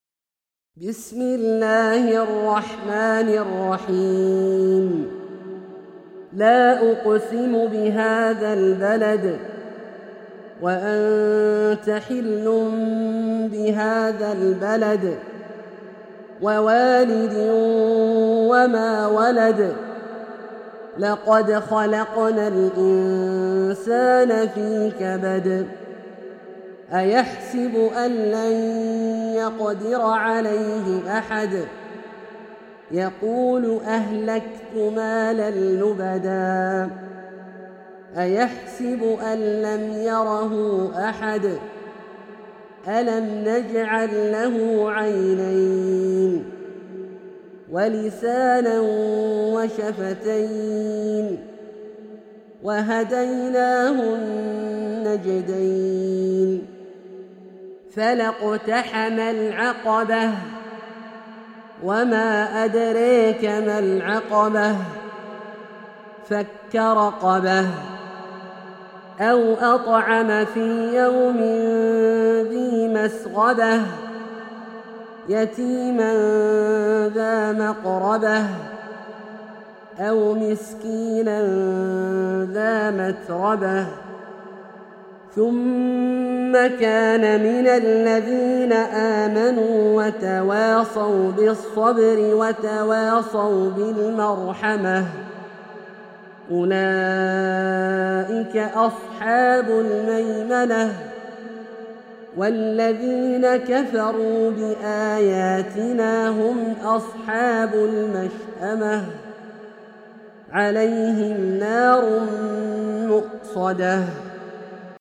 سورة البلد - برواية الدوري عن أبي عمرو البصري > مصحف برواية الدوري عن أبي عمرو البصري > المصحف - تلاوات عبدالله الجهني